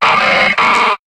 Cri de Tengalice dans Pokémon HOME.